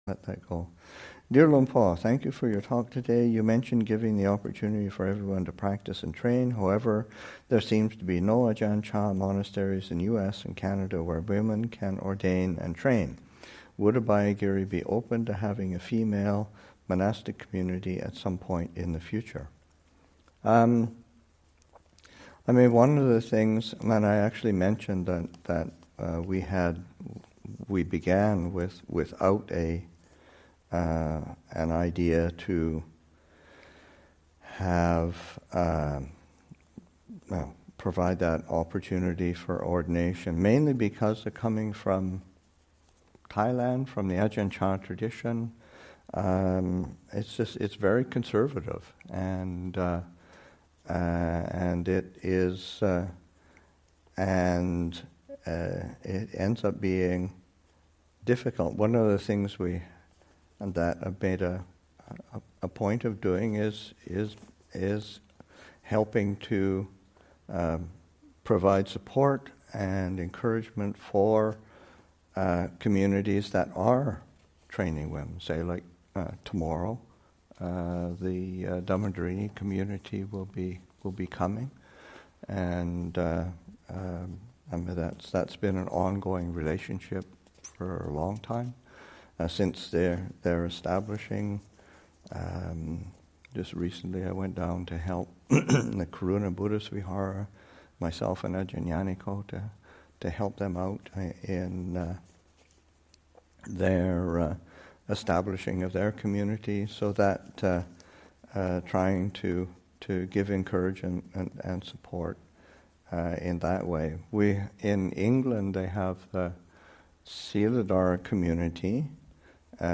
Abhayagiri 25th Anniversary Retreat, Session 19 – Jun. 14, 2021